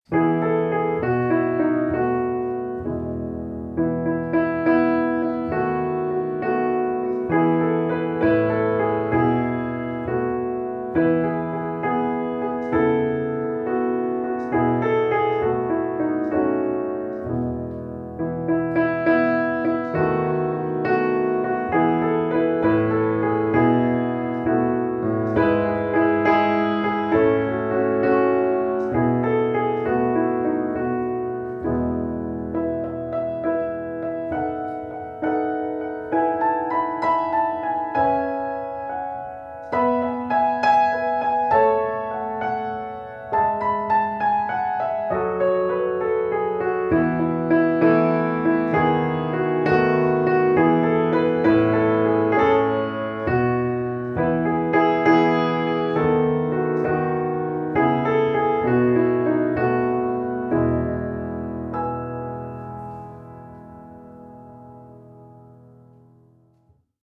караоке
минусовка